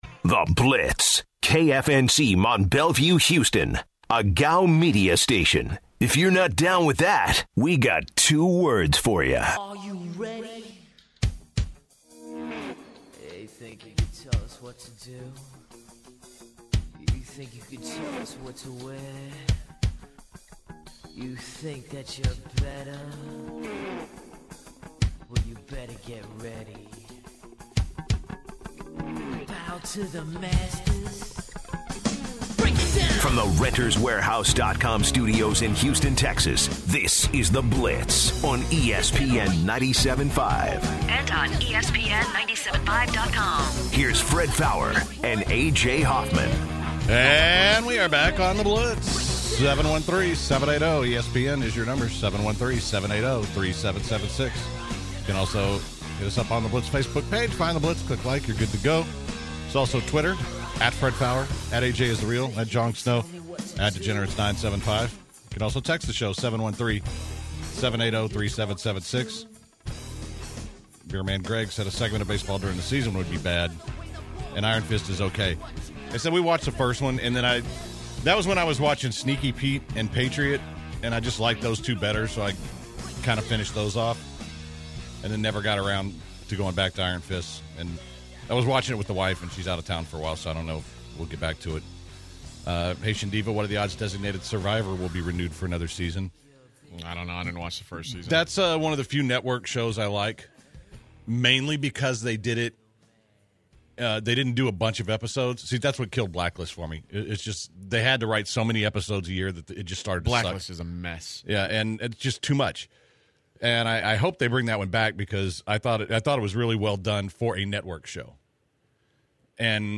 on the second hour the guys talk about Deshaun Watson and what kind of player he will be, they take more what are the odds calls and the Zaddok Jewlers Gem Of the day.